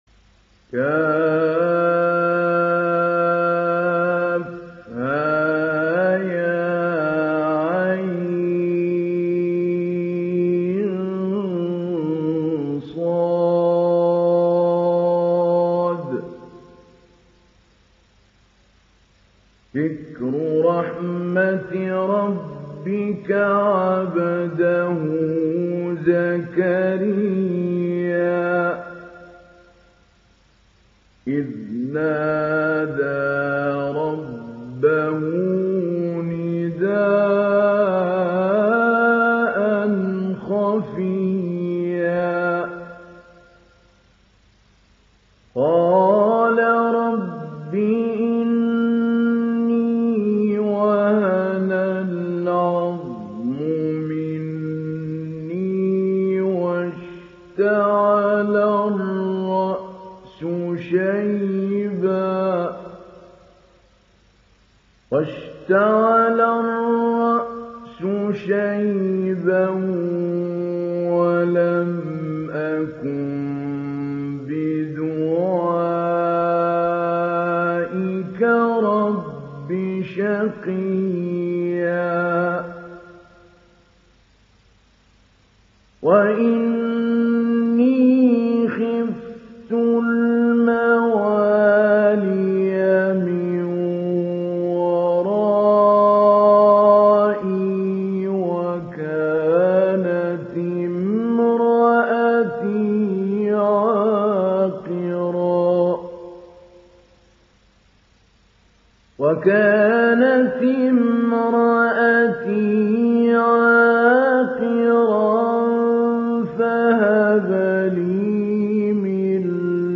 ডাউনলোড সূরা মারইয়াম Mahmoud Ali Albanna Mujawwad